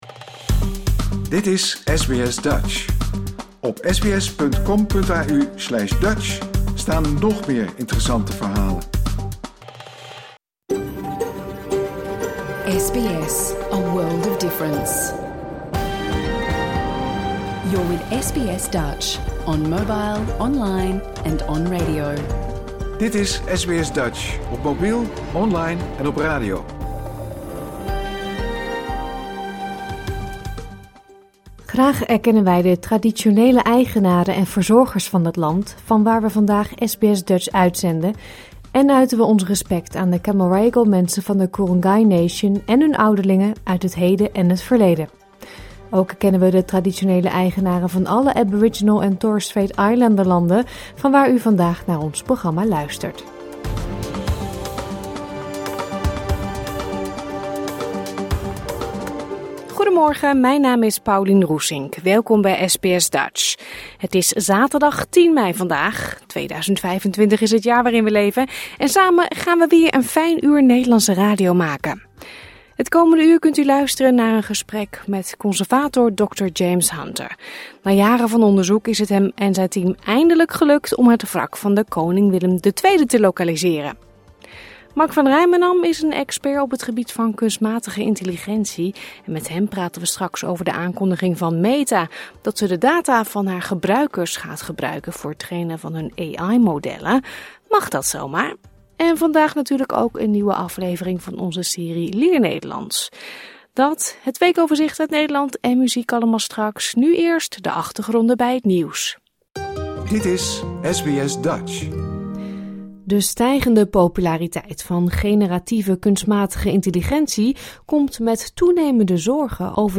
SBS Dutch gemist? Luister hier de uitzending van zaterdag10 mei 2025 (bijna) integraal terug.